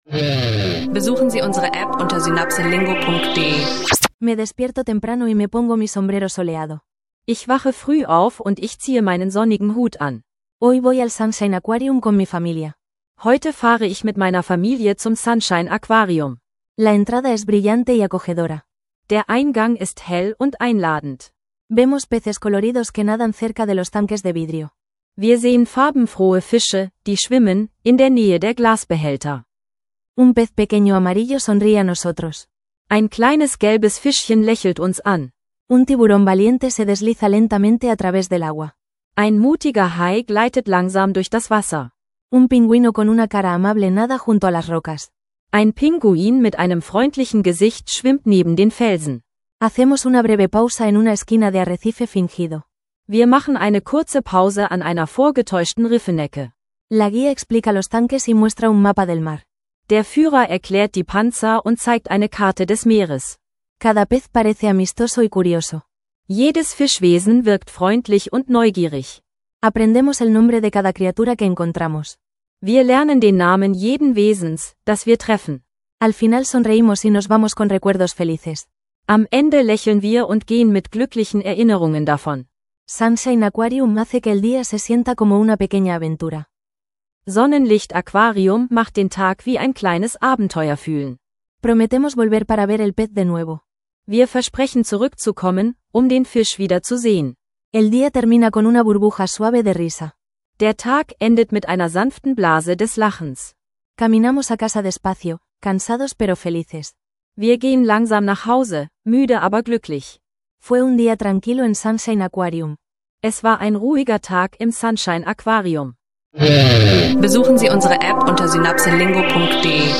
Eine einfache Bilingual-Erzählung über den Besuch des Sunshine Aquariums mit farbenfrohen Fischen, Haien und einem freundlichen Pinguin – ideal für Spanisch lernen leicht gemacht